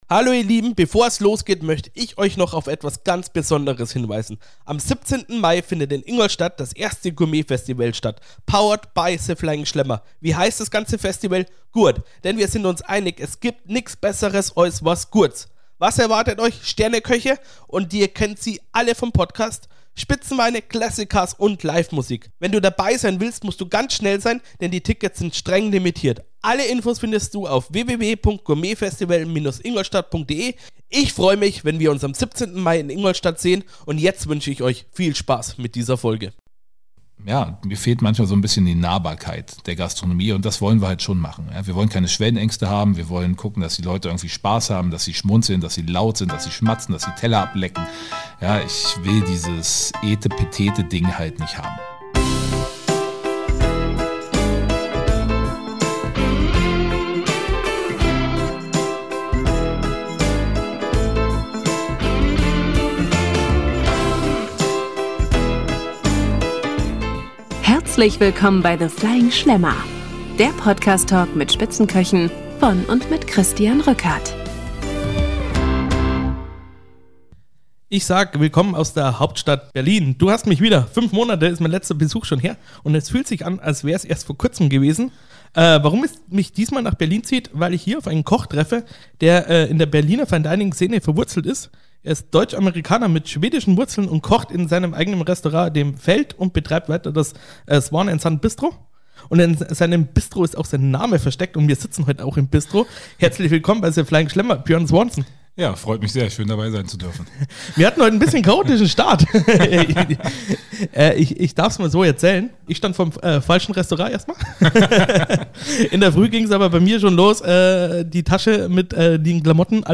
- The Flying Schlemmer - Der Podcast Talk mit Sterneköchen
In dieser Folge wird’s laut, direkt und garantiert nicht etepetete!